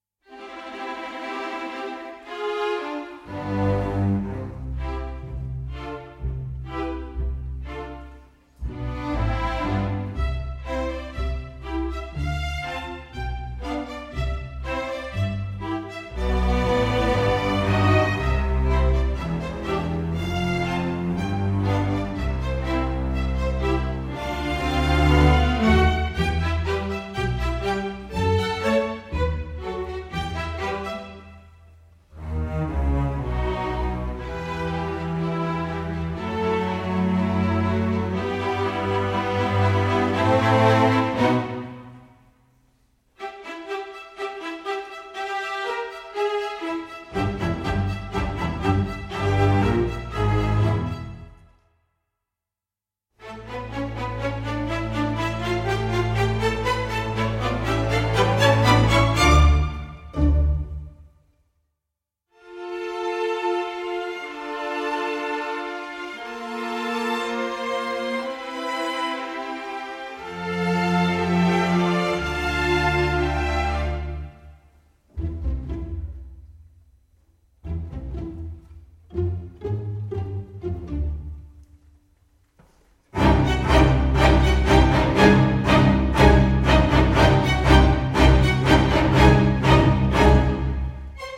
Orchestre Juniors